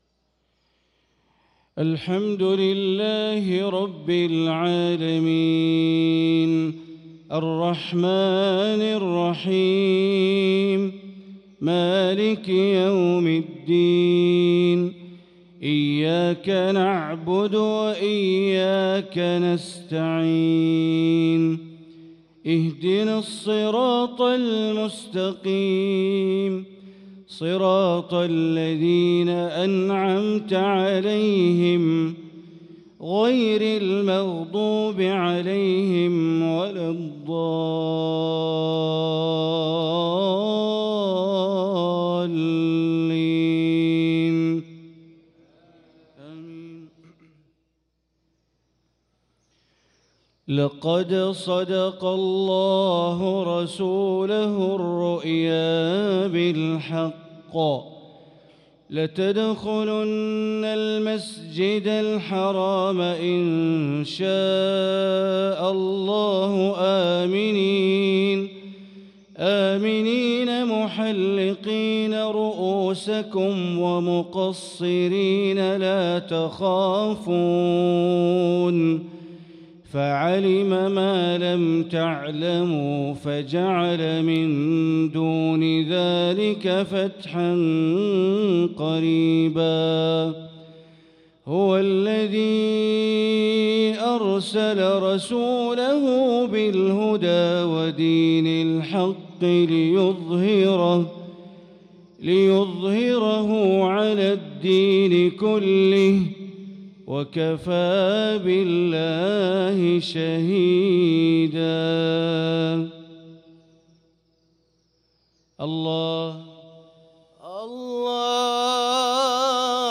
صلاة المغرب للقارئ بندر بليلة 27 رجب 1445 هـ
تِلَاوَات الْحَرَمَيْن .